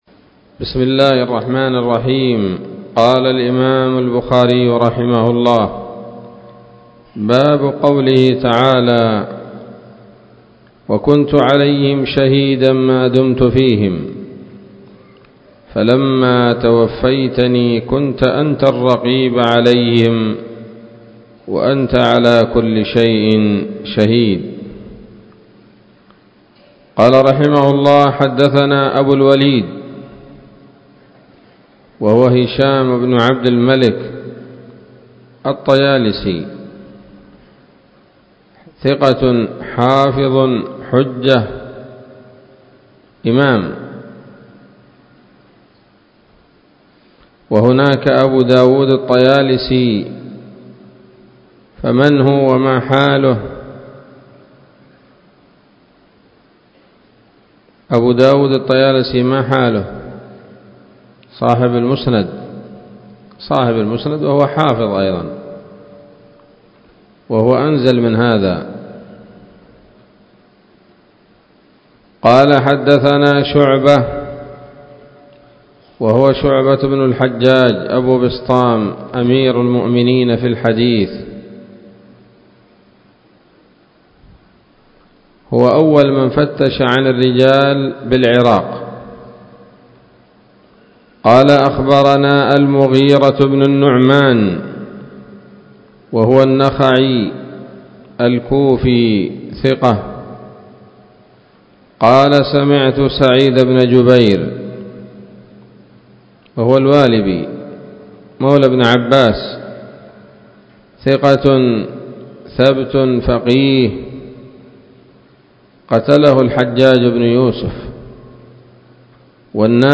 الدرس السابع والتسعون من كتاب التفسير من صحيح الإمام البخاري